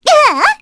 Cleo-vox-get_03_kr.wav